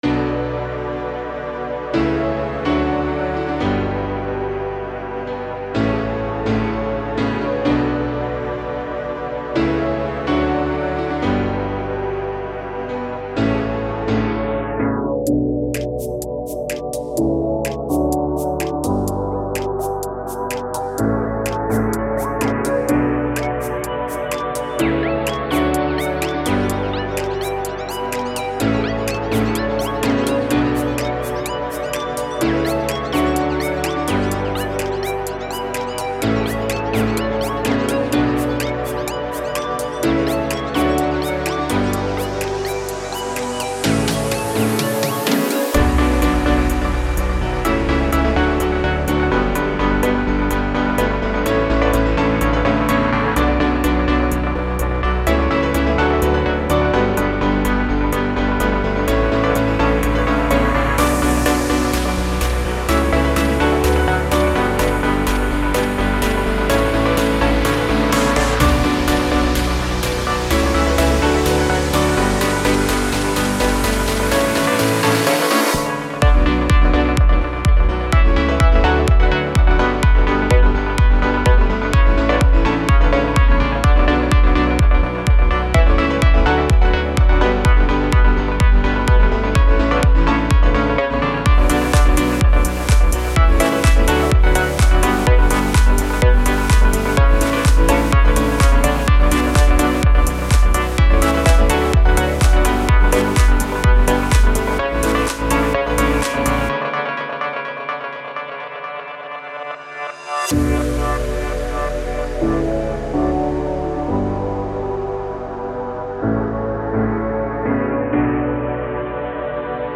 Tempo ist 126bpm Genre ist glaub ich Progressive House bin mir da aber nicht sicher Schonmal danke im Vorraus Anhänge Sounds Of Pluck.mp3 5,8 MB